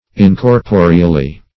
incorporeally - definition of incorporeally - synonyms, pronunciation, spelling from Free Dictionary Search Result for " incorporeally" : The Collaborative International Dictionary of English v.0.48: Incorporeally \In`cor*po"re*al*ly\, adv.
incorporeally.mp3